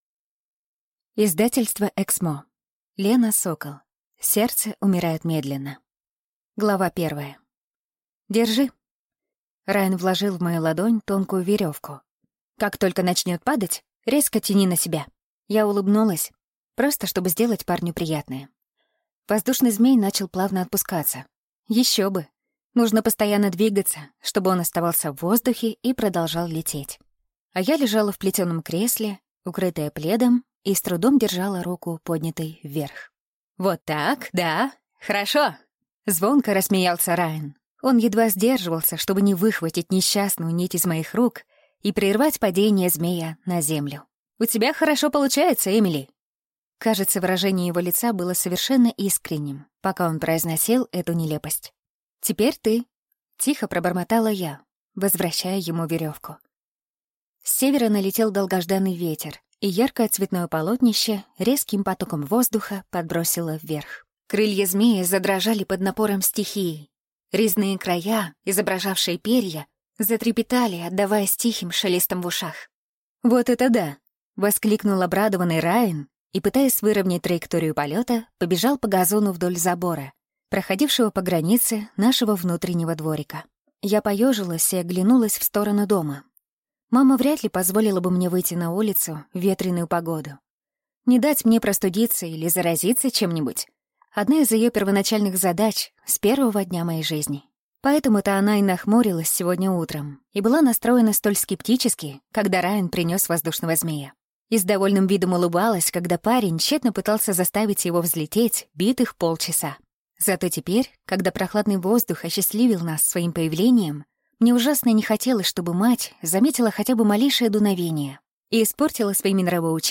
Аудиокнига Сердце умирает медленно | Библиотека аудиокниг